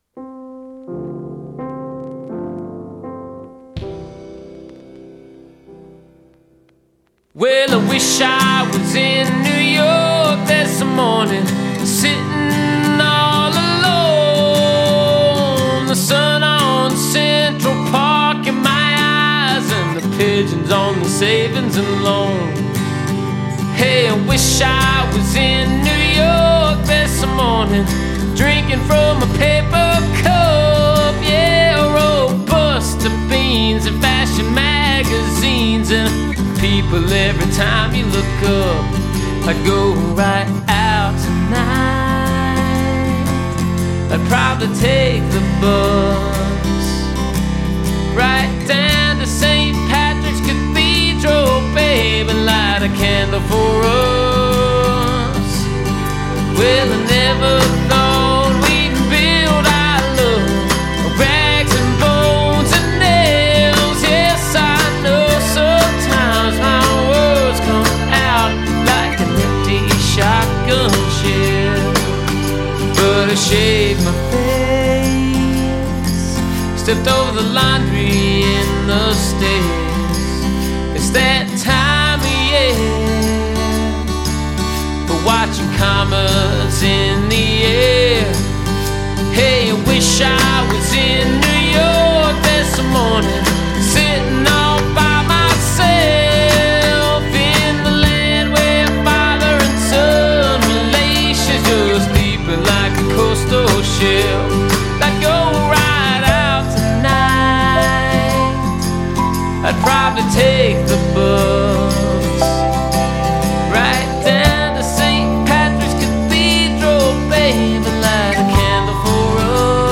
alt country band